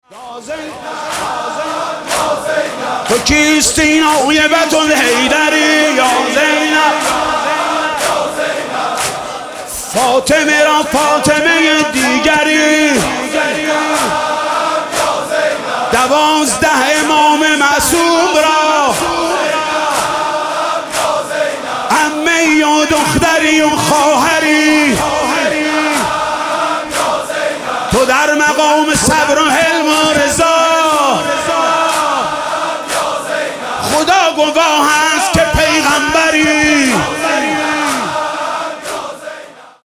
تو كيستى نايبة الحيدرى/ حاج محمود کریمی/ شب چهارم محرم96
دانلود مداحی تو كيستى نايبة الحيدرى/ حاج محمود کریمی/ شب چهارم محرم96